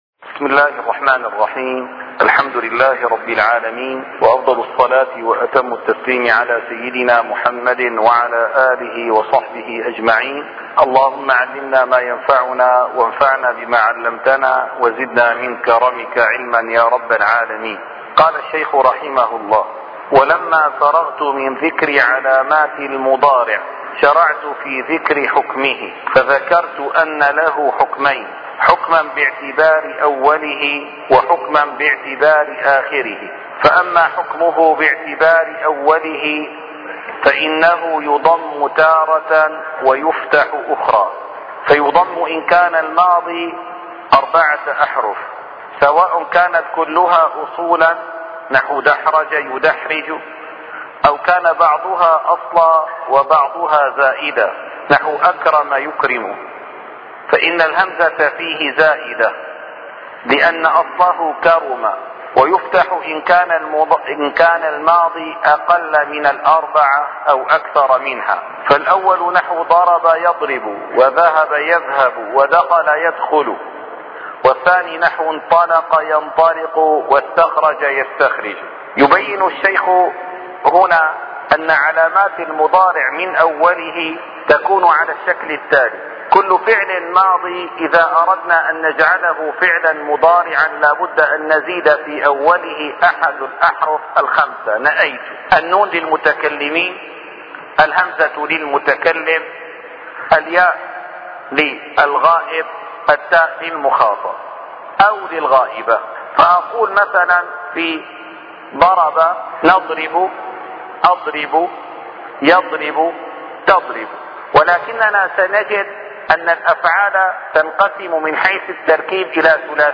- الدروس العلمية - شرح قطر الندى وبل الصدى - ولما فرغت من ذكر علامات المضارع شرعت في ذكر حكمه: فذكرت أنّ له حكمين: حكماً باعتبار أوله, وحكماً باعتبار آخره... (ص72)